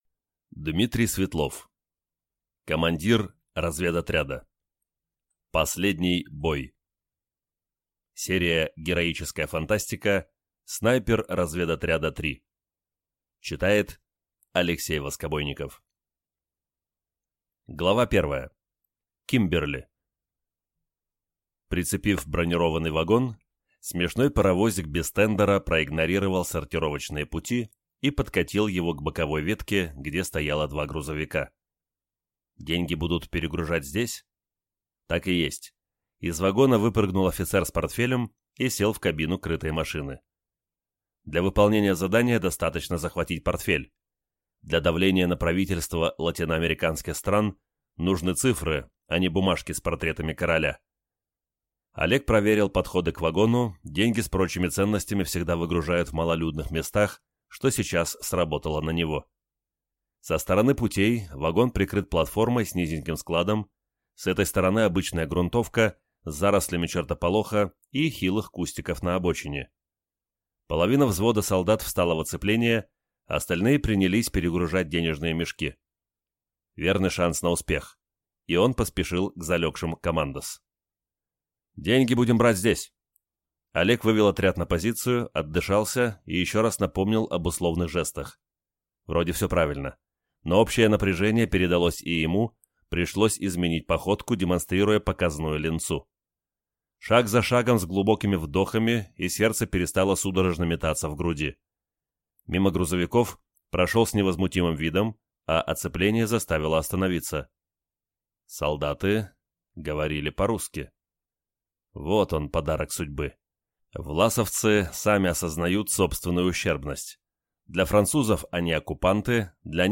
Аудиокнига Командир разведотряда. Последний бой | Библиотека аудиокниг